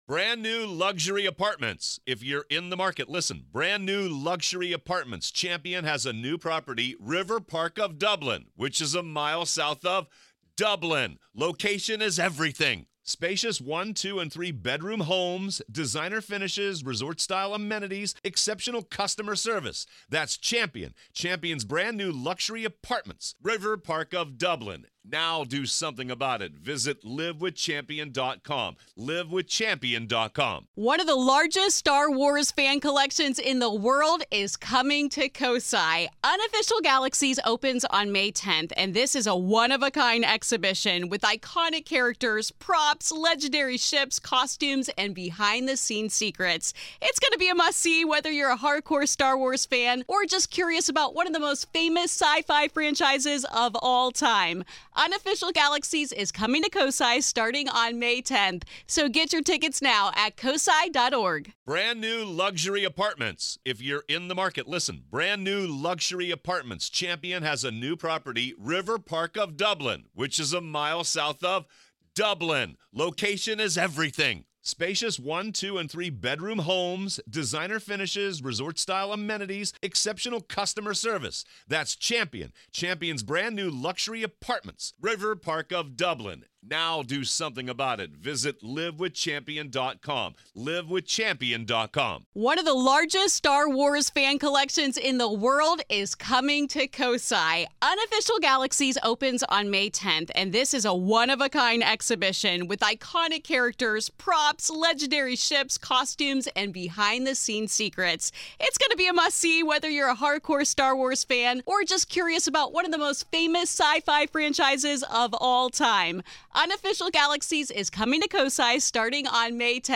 If you're enjoying our interviews and conversations about "The Dead", why not listen ad-free?